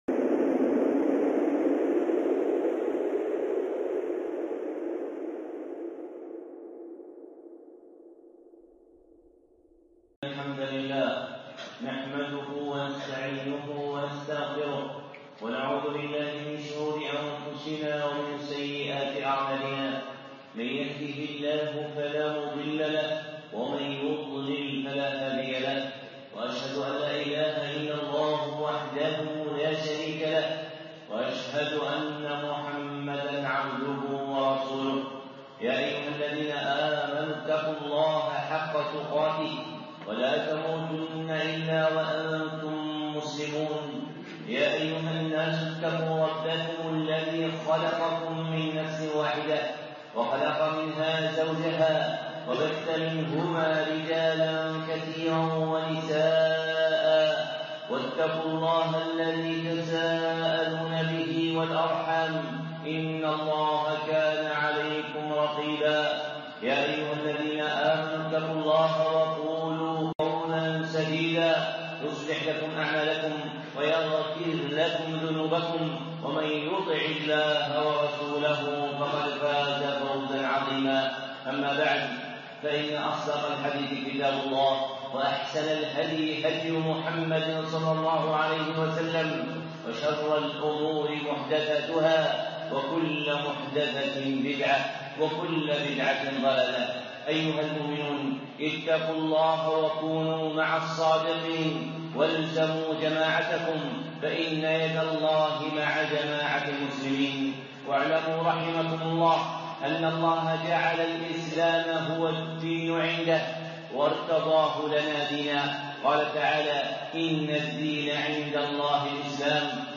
خطبة (ما لا يعني ويعني) الشيخ صالح العصيمي